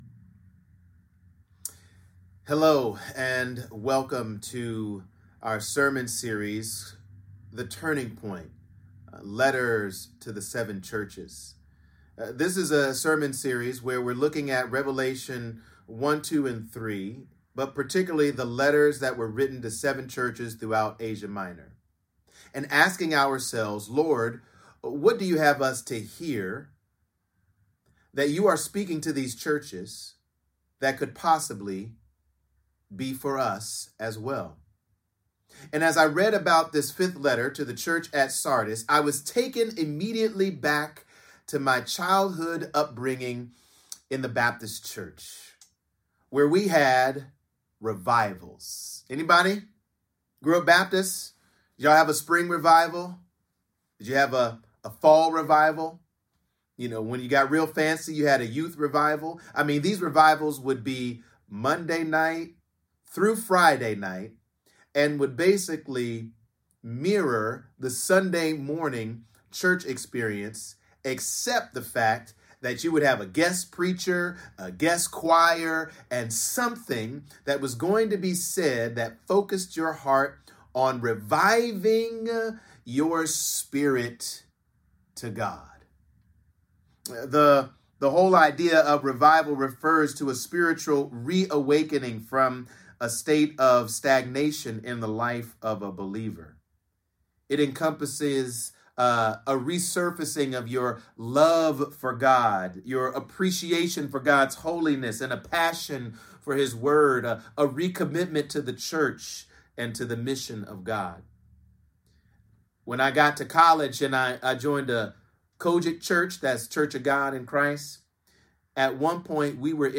Sermons | Sanctuary Columbus Church